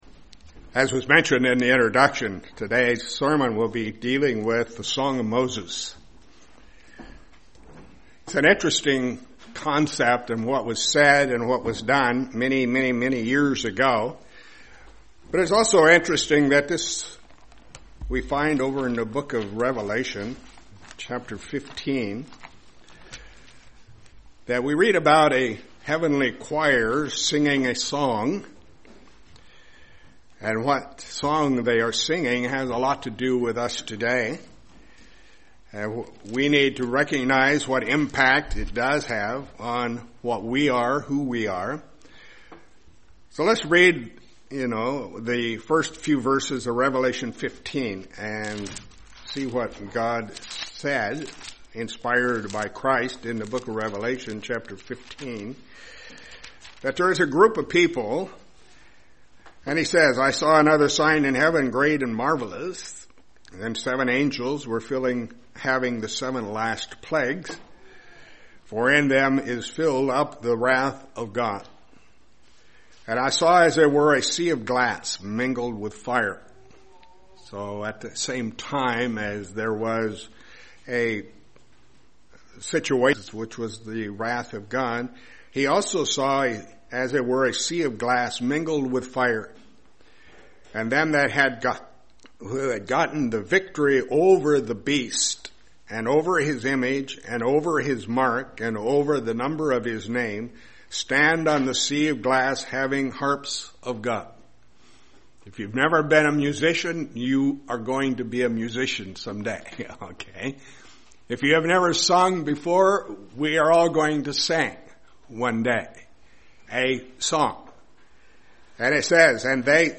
Given in Lehigh Valley, PA
UCG Sermon Studying the bible?